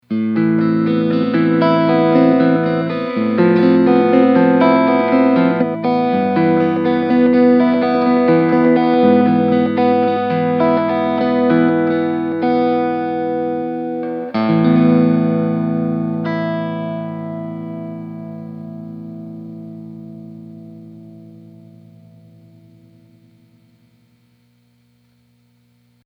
This was recorded straight into my computer with no EQ, no nothin’ attached. It’s a clean, dry signal that simply captures the tone of my Strat.